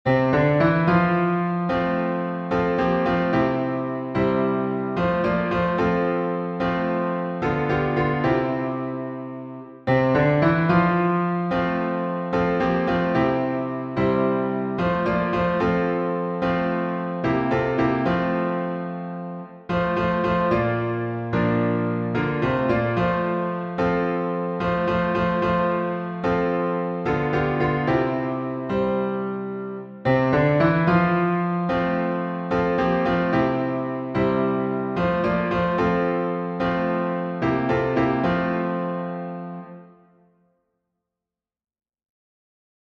Heavenly Sunlight — F major.